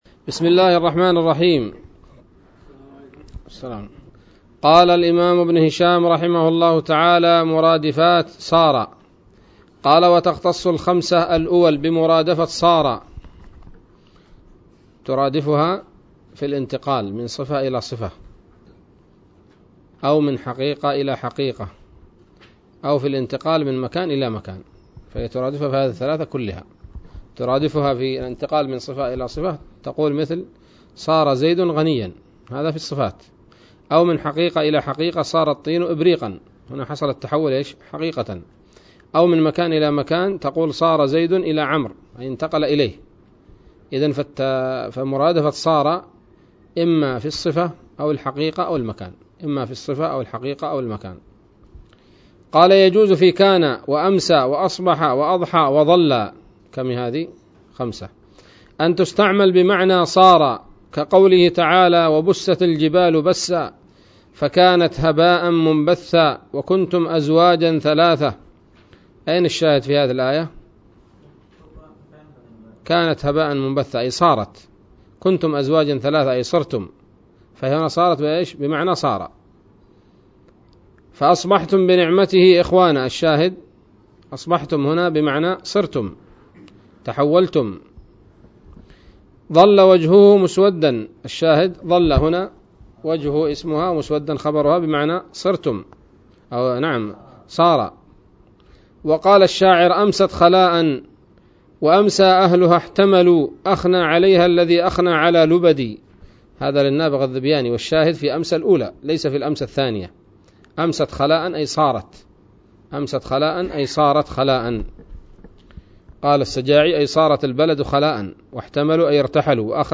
الدرس الثامن والخمسون من شرح قطر الندى وبل الصدى